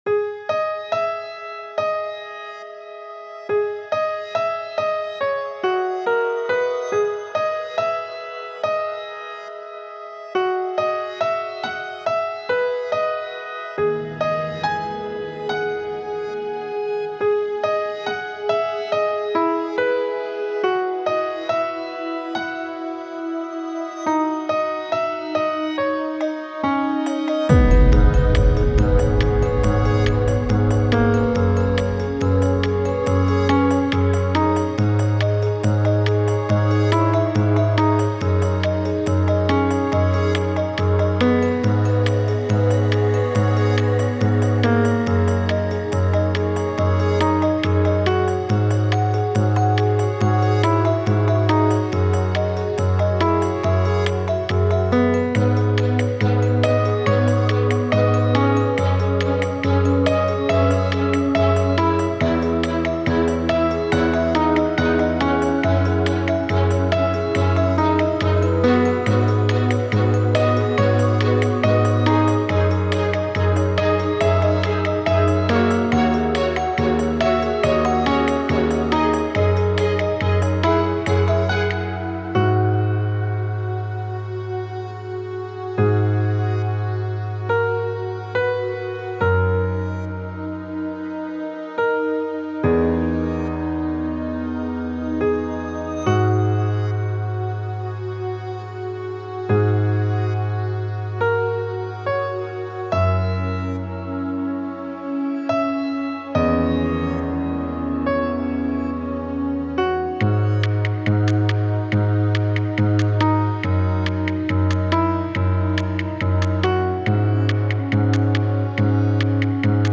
Orchestral / Cinematic